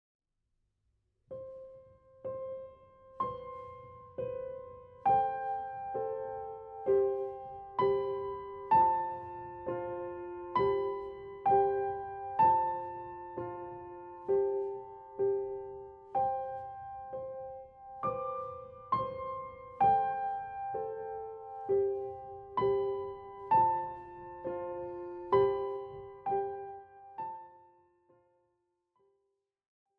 41 leichte Klavierstücke
Besetzung: Klavier